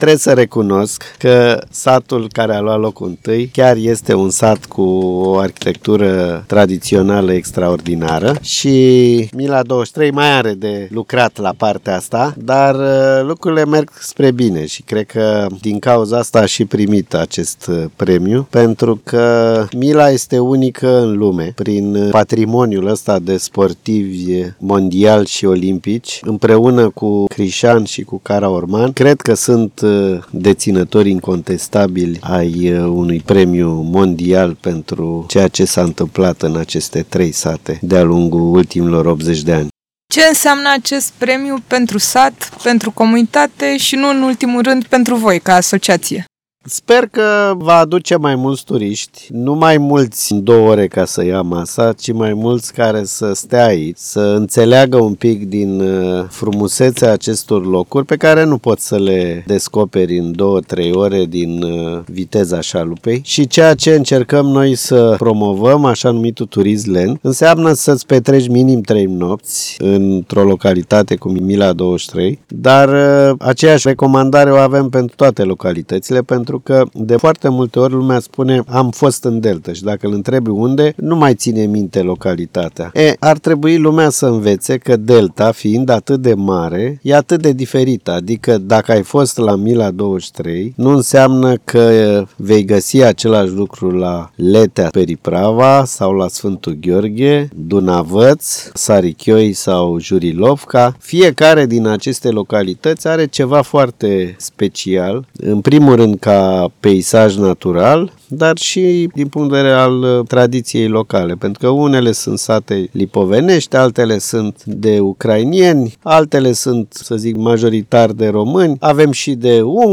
Mila 23 — între tradiție, natură și turism lent. Interviu